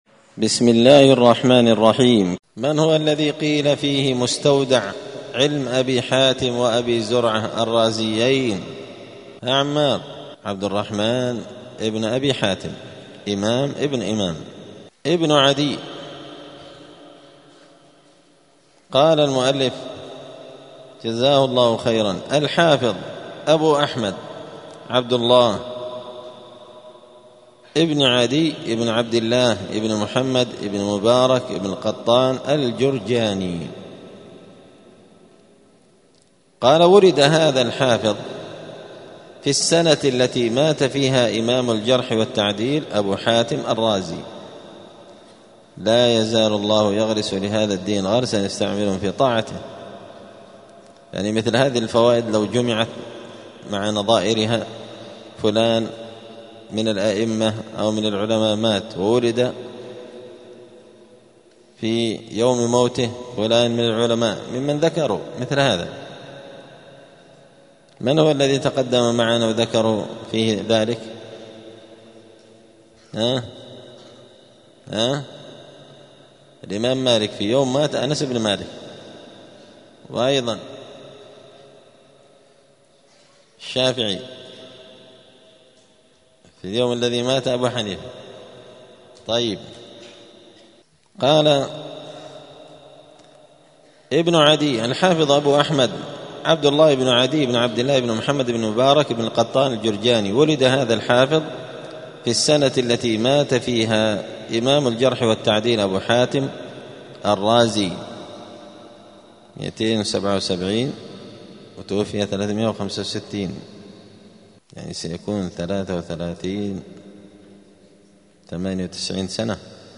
دار الحديث السلفية بمسجد الفرقان قشن المهرة اليمن
*الدرس التاسع والتسعون (99) باب التعريف بالنقاد ابن عدي*